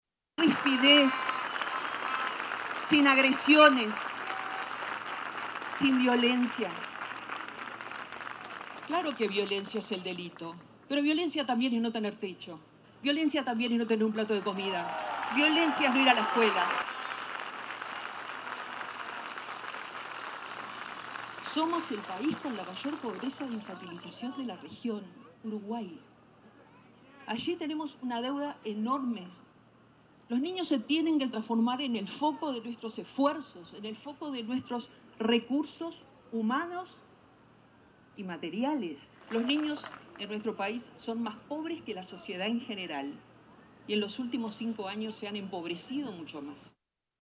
La lista 609 del Frente Amplio, encabezada por el senador Alejandro Sánchez, lanzó su campaña de cara a las próximas elecciones nacionales de octubre, y también marcó la primera presencia de la candidata al Senado Blanca Rodríguez en un acto de militancia, luego de su presentación en la Huella de Seregni.